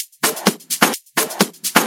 Index of /VEE/VEE Electro Loops 128 BPM
VEE Electro Loop 223.wav